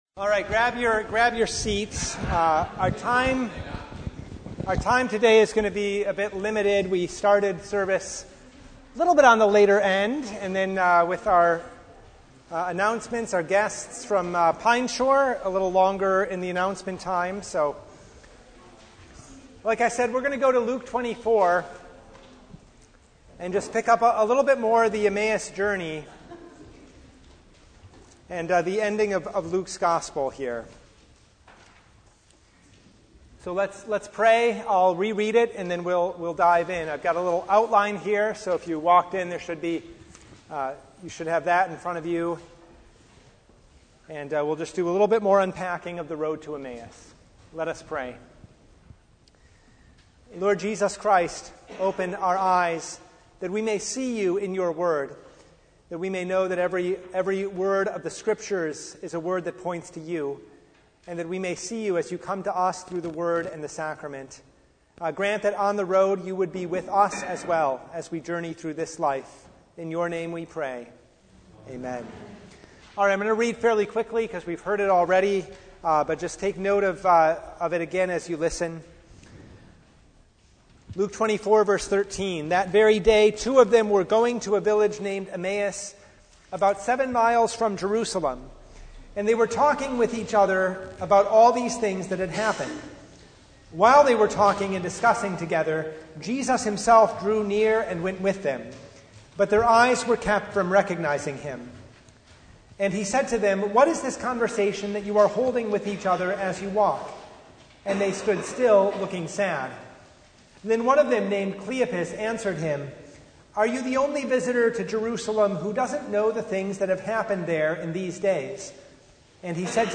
Passage: Luke 24:13-35 Service Type: Bible Hour